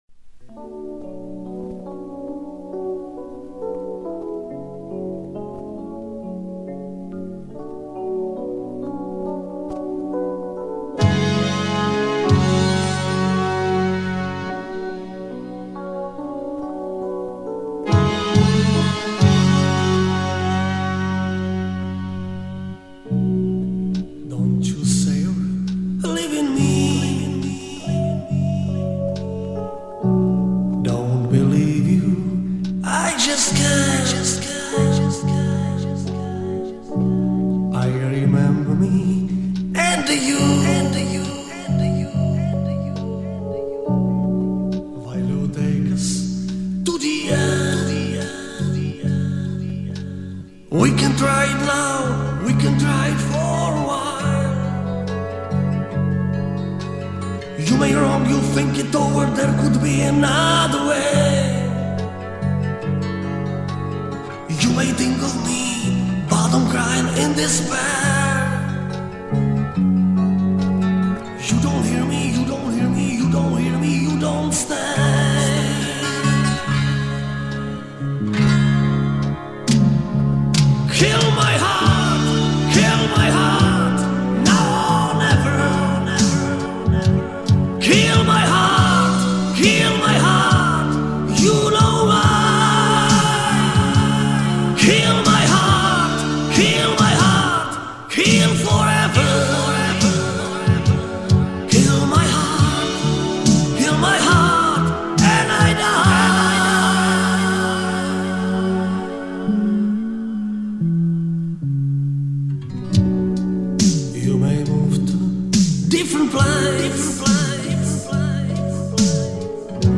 Так было оцифровано моим приятелем с винила.